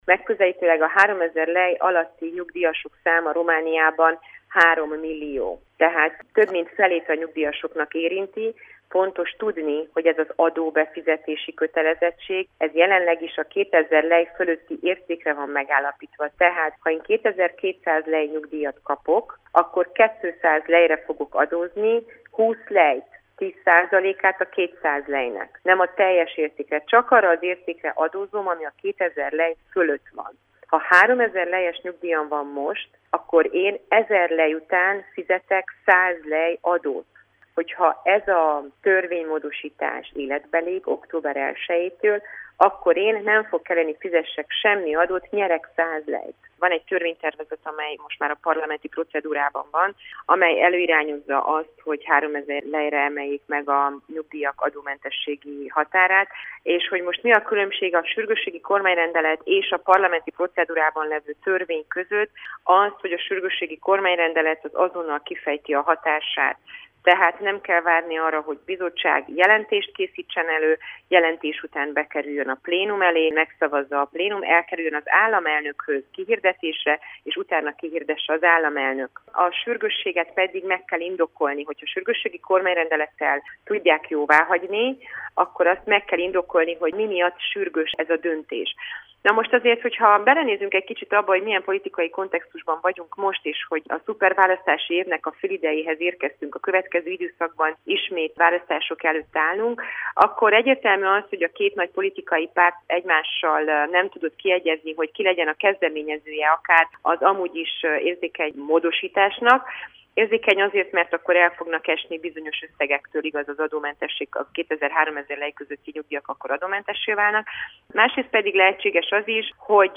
Csép Andrea parlamenti képviselő válaszol.